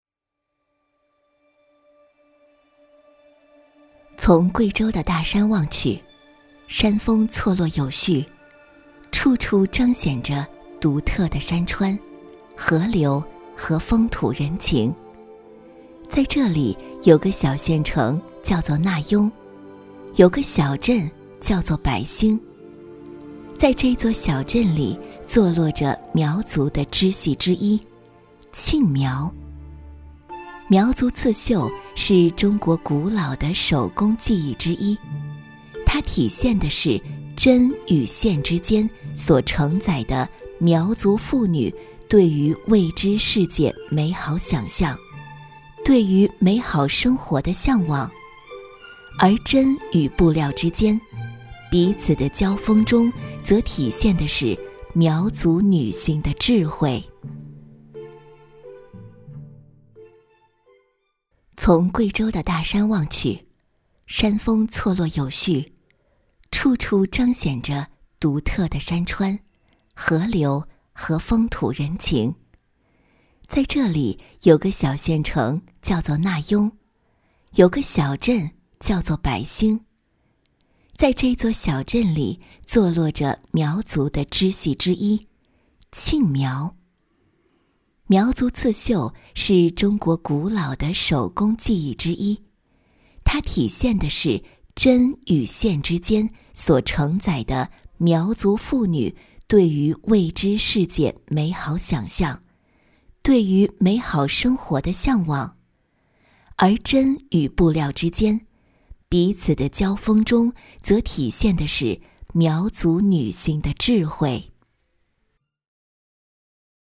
纪录片-女19-缓慢-箐染.mp3